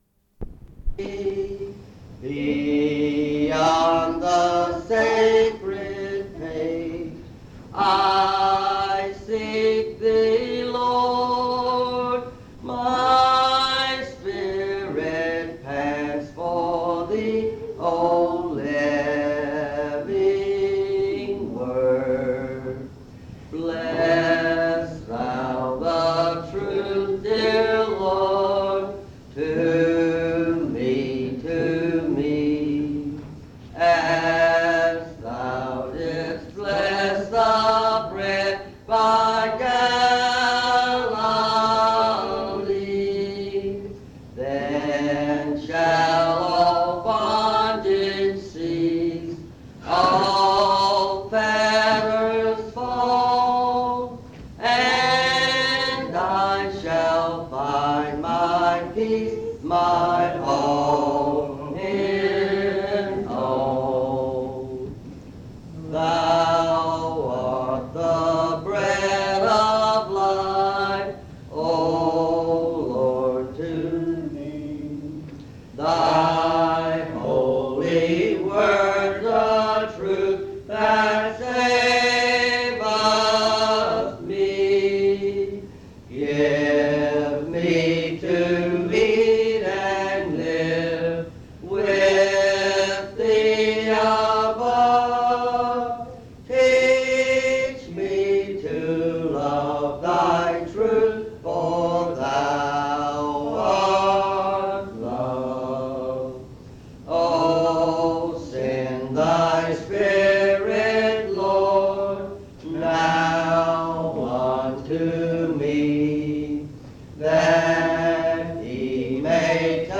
Message
at Monticello Primitive Baptist Church